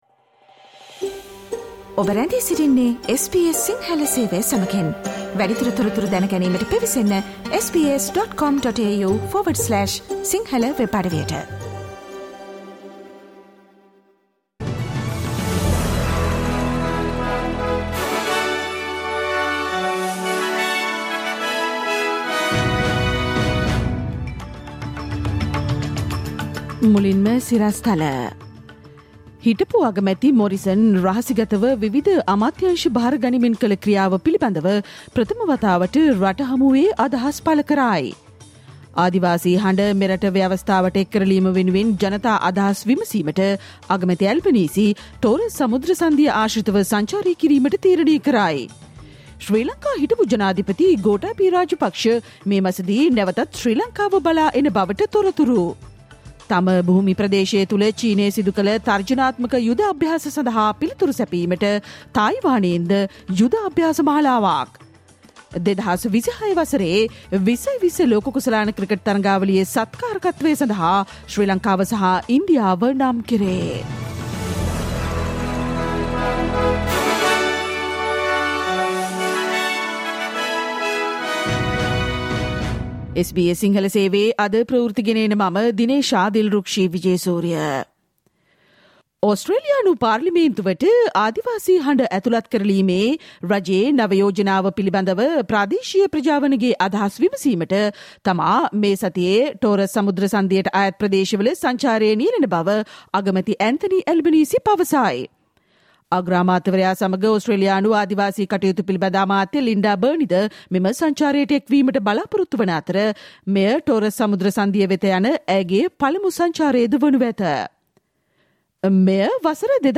Listen to the SBS Sinhala Radio news bulletin on Thursday 18 August 2022